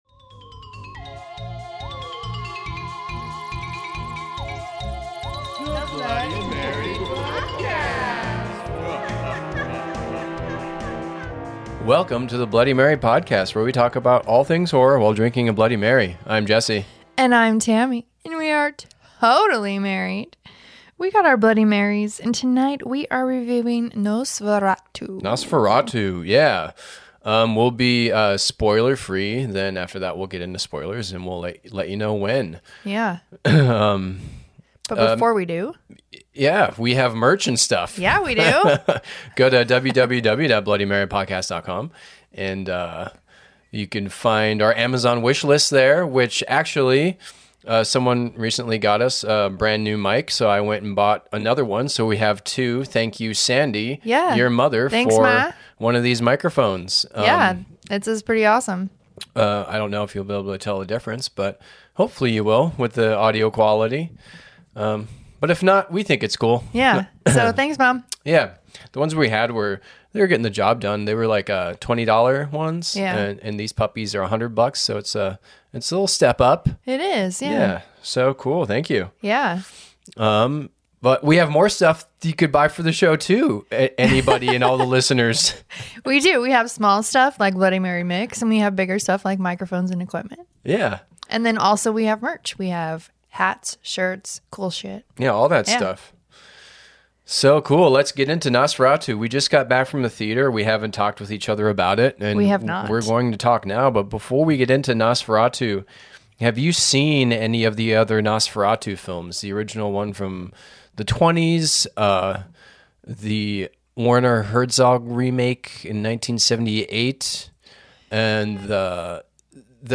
a totally rad couple, as they have a heart-to-heart about all things horror while drinking Bloody Marys.